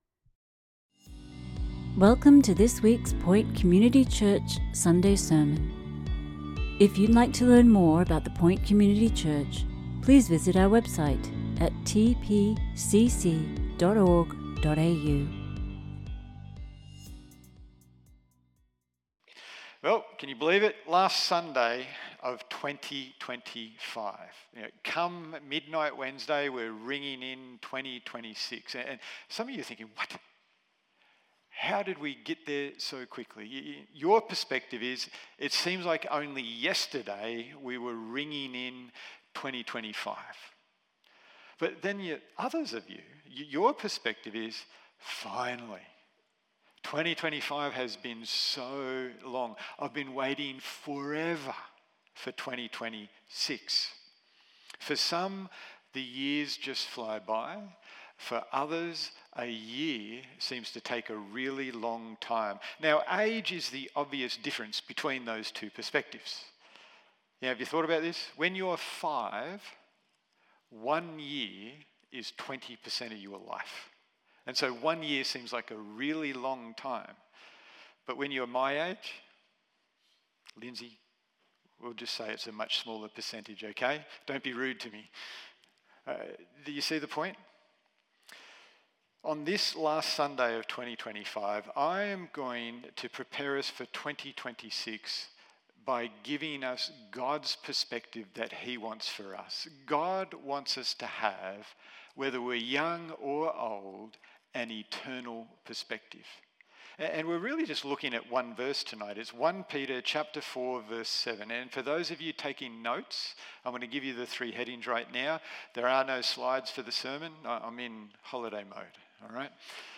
Sermons | The Point Community Church